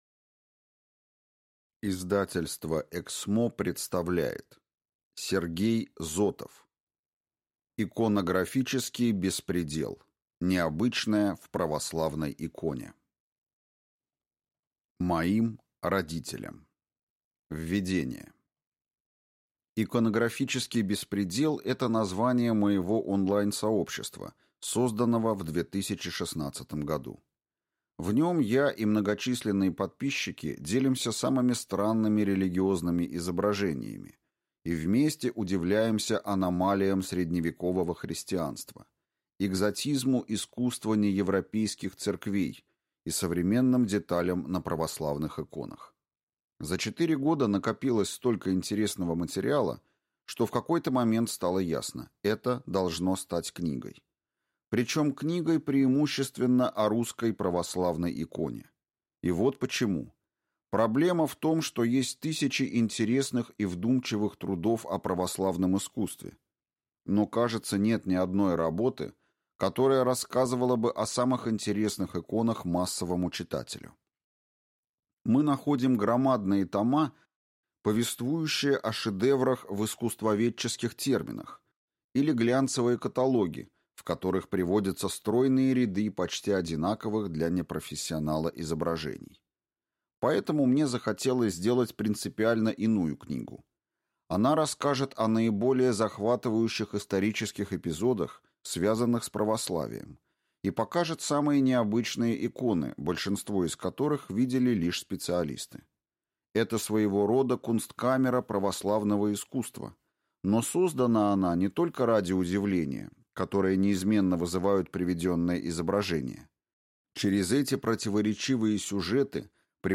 Аудиокнига Иконографический беспредел. Необычное в православной иконе | Библиотека аудиокниг